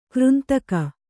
♪ křntaka